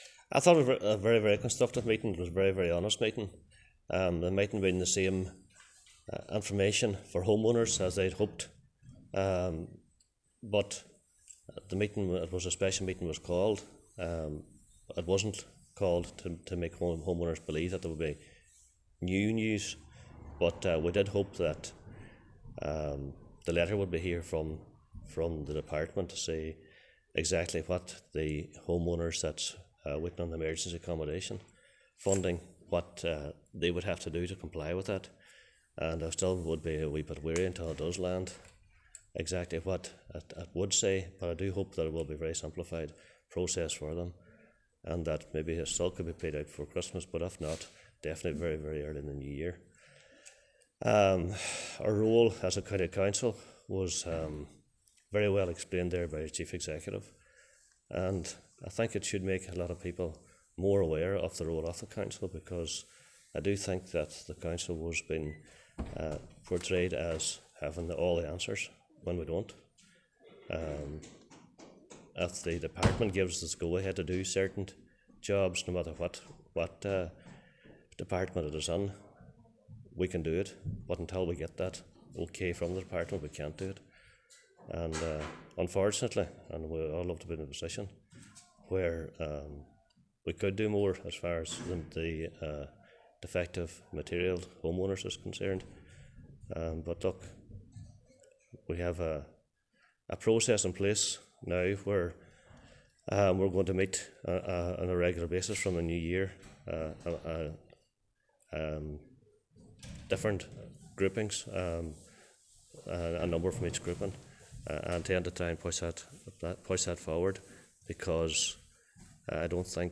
Cathaoirleach Cllr Liam Blaney says the Council would love a freer arm to be able to do more to help defective block homeowners but unfortunately, they must await the government go ahead before proceeding with some aspects of the scheme..
Liam-Blaney.wav